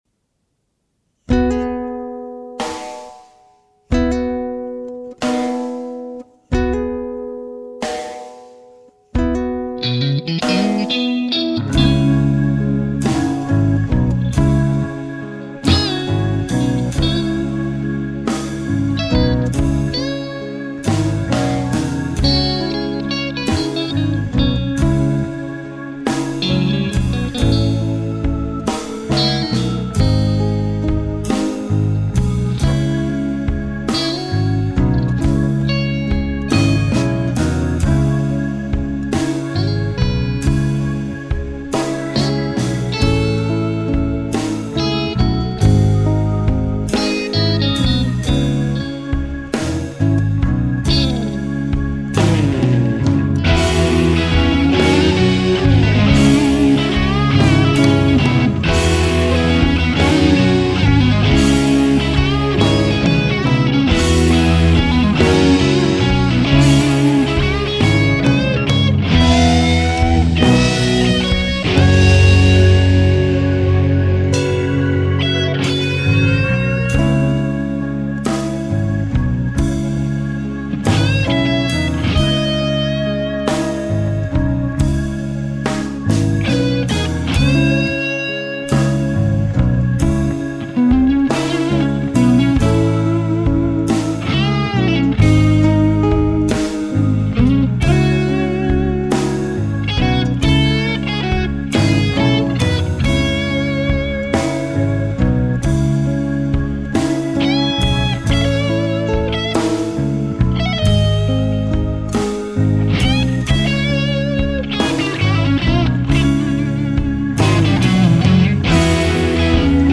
Mit dem langsamen Tempo habe ich anfangs doch ein paar (Timing-)Problemchen gehabt. Danke übrigens für das schöne Backing.